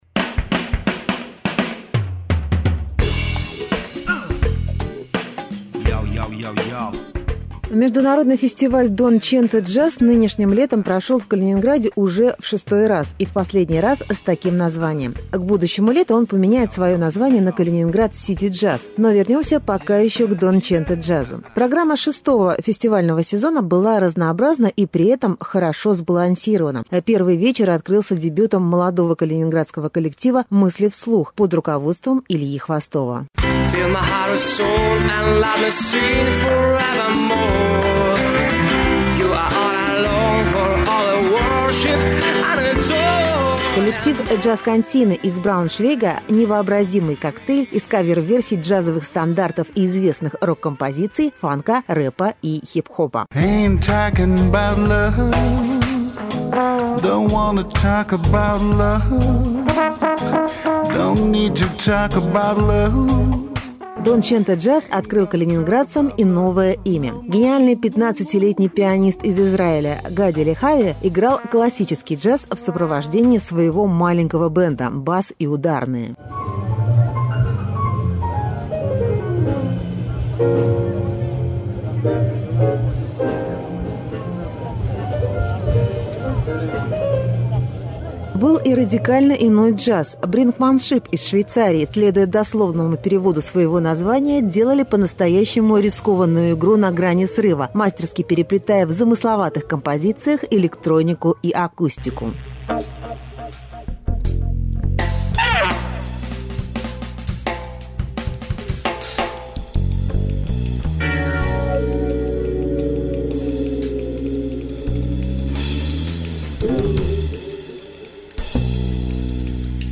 Джаз на берегу Балтийского моря (Калининград)